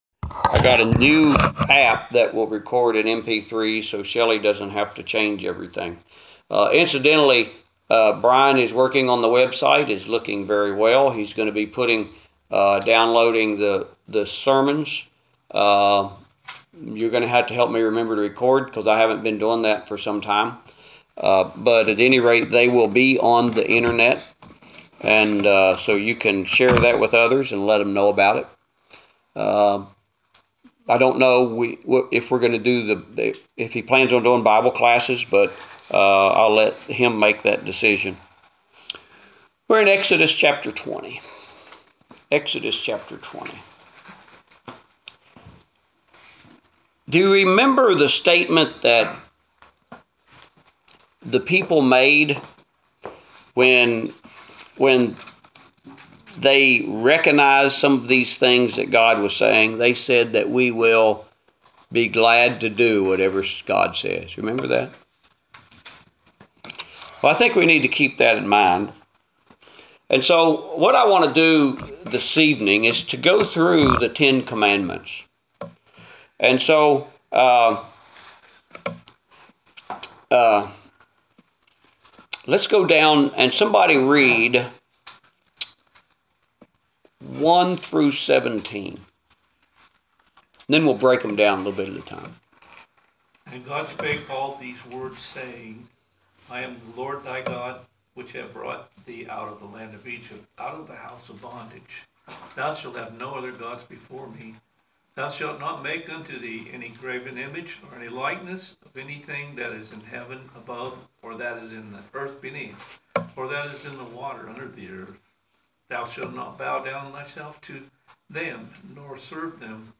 Adult Bible Class: 9/27/2017